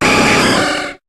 Cri de Poissoroy dans Pokémon HOME.